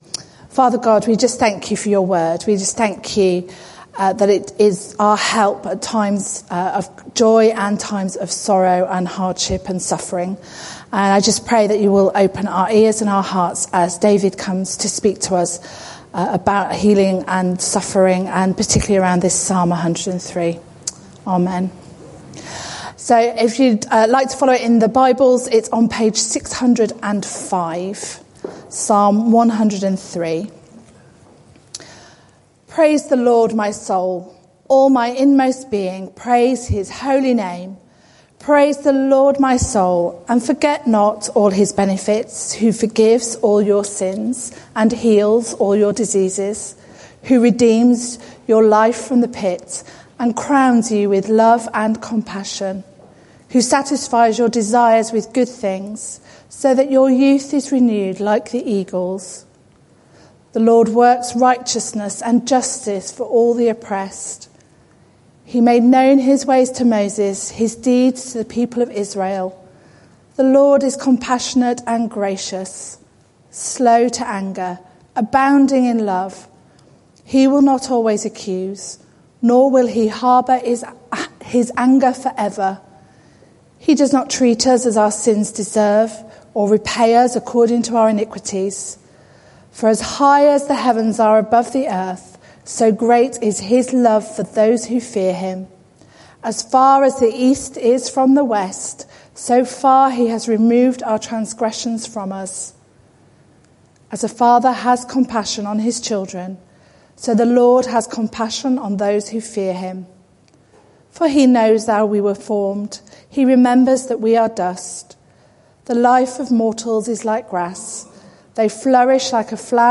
This sermon is part of a series: 4 March 2018, 6:20 pm - Does God Heal?